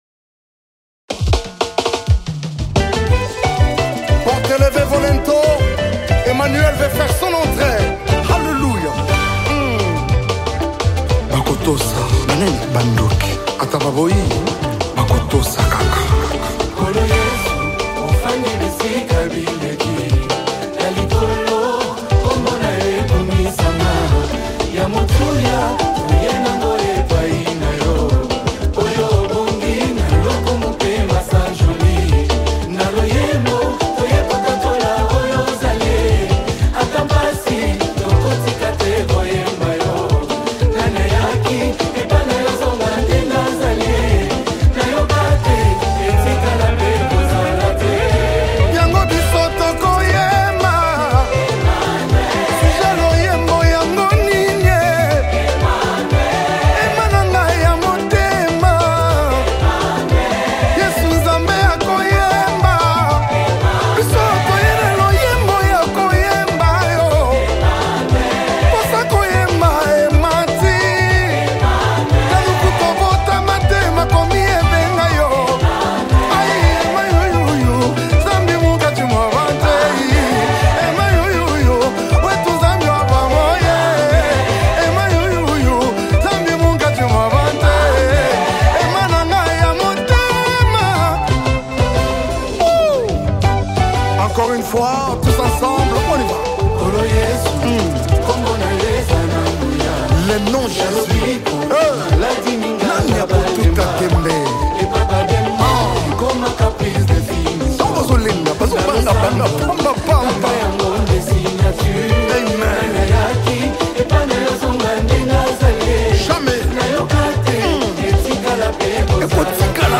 Congo Gospel Music
a powerful and uplifting worship anthem